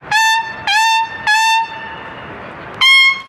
Toque de corneta 1
aerófono
corneta
llamada
militar